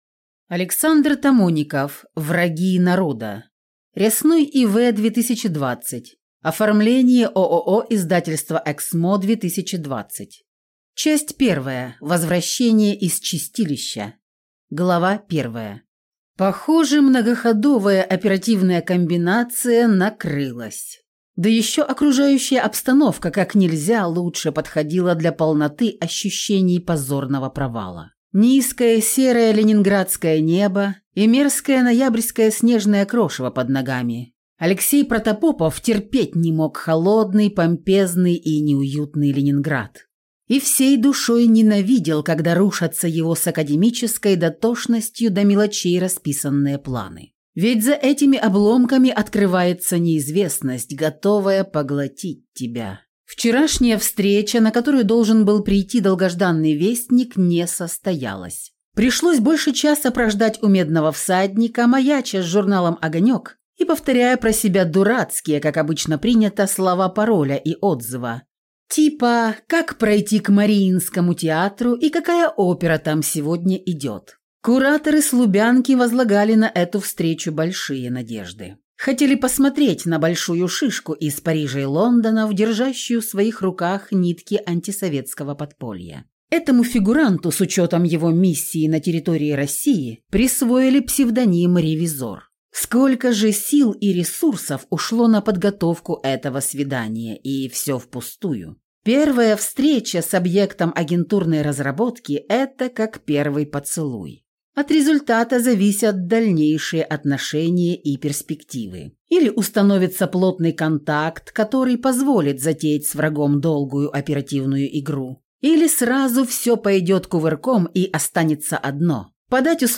Аудиокнига Враги народа | Библиотека аудиокниг